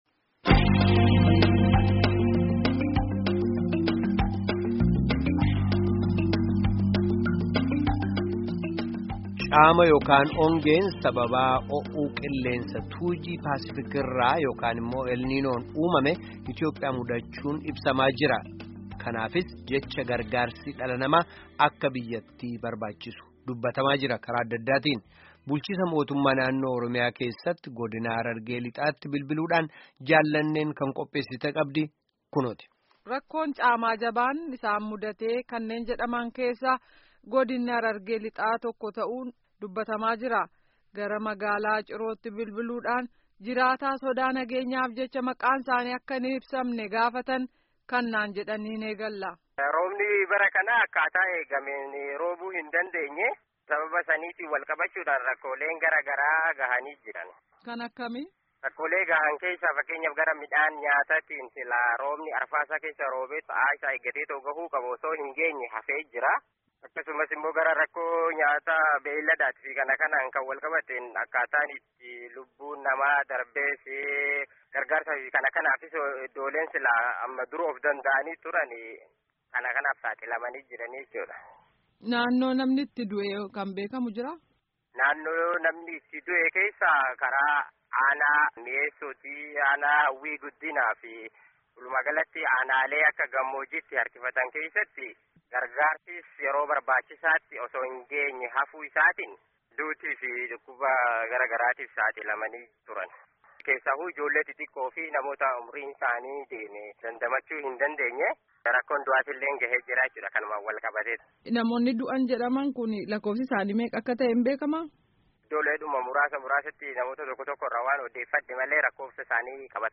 Gaaffii fi deebii caama Harargee dhiyaa ilaalchisuun geggeessame dhaga'aa